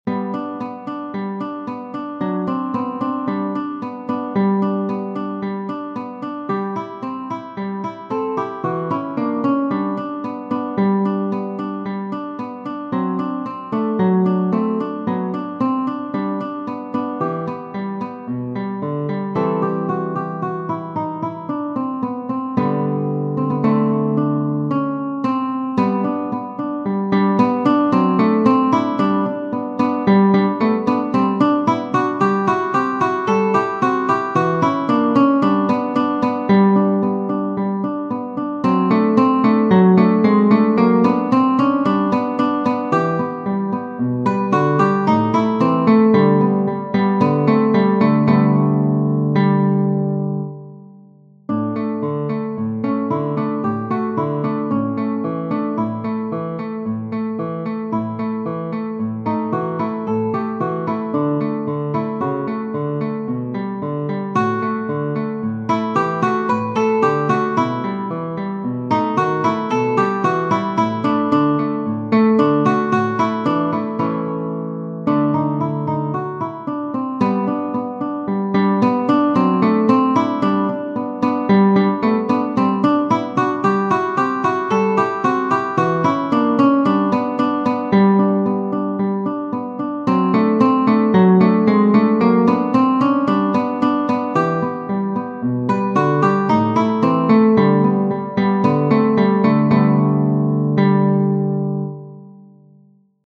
Genere: Classica